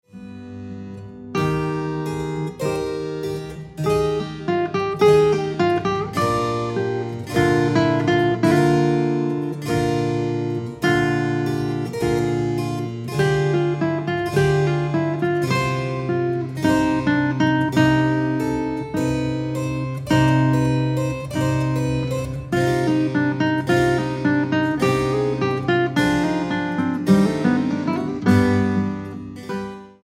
guitarra
clavecín
música original para guitarra y clavecín